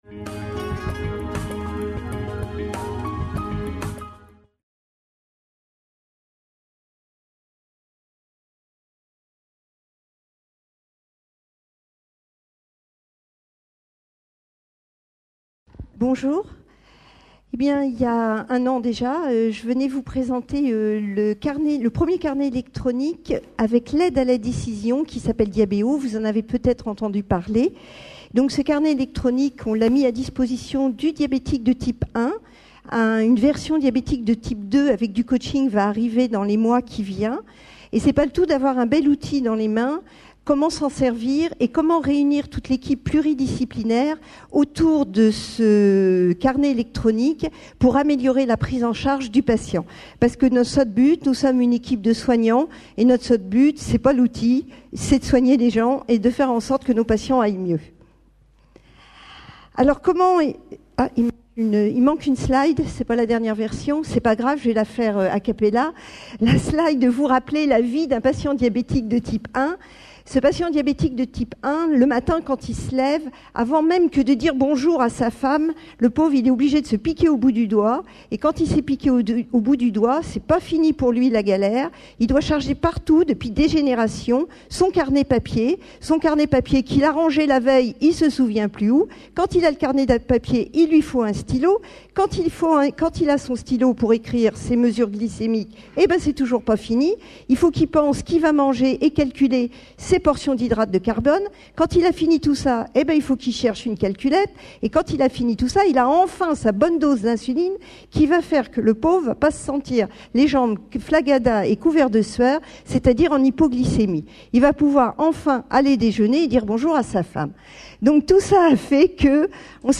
Conférence enregistrée lors du congrès international FORMATIC PARIS 2011.